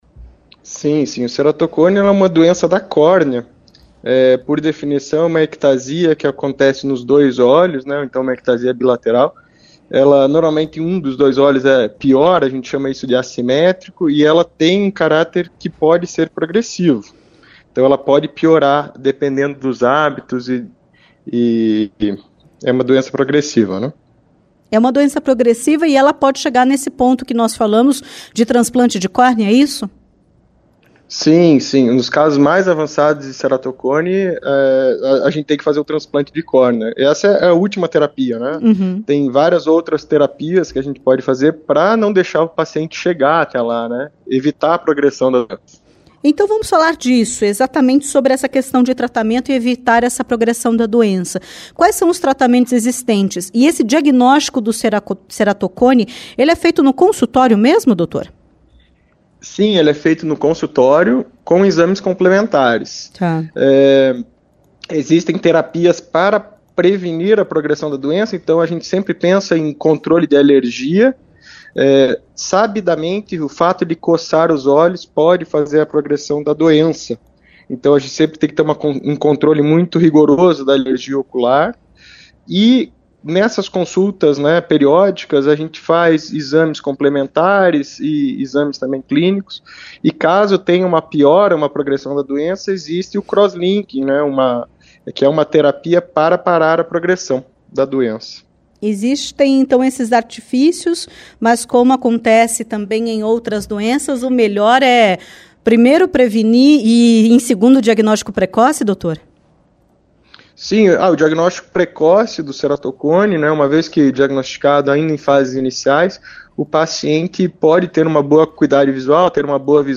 Entrevista-DR-31-10.mp3